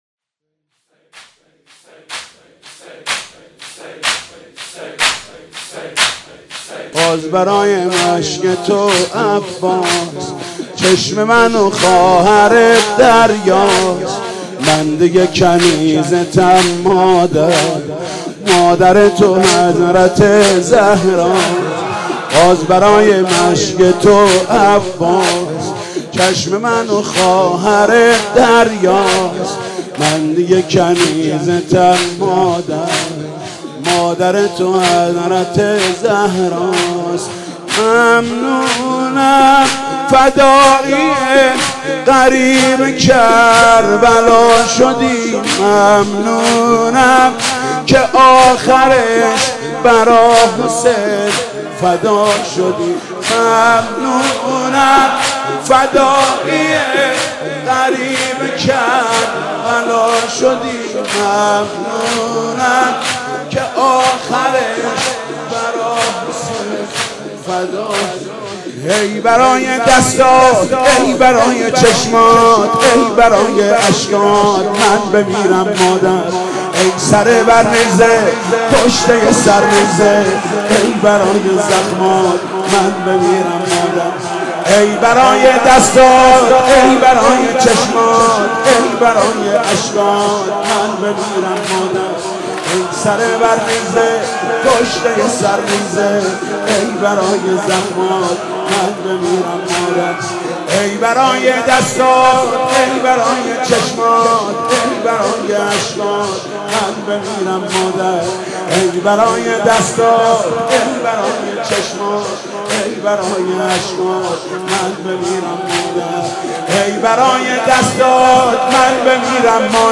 مناسبت : وفات حضرت ام‌البنین سلام‌الله‌علیها
مداح : محمود کریمی قالب : زمینه